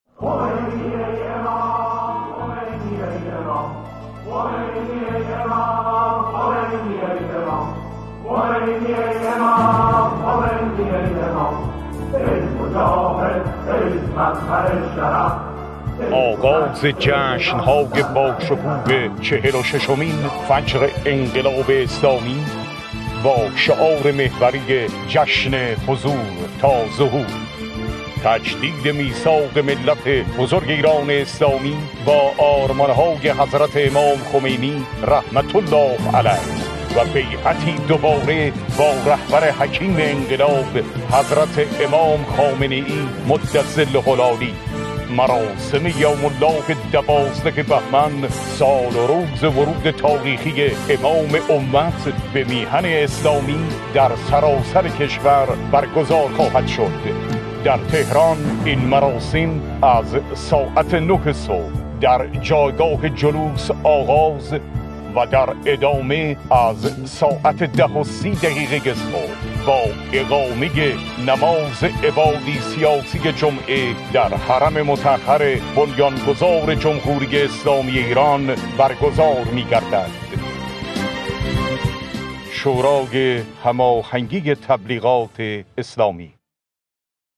تیزر اطلاع رسانی مراسم یوم الله 12 بهمن 1403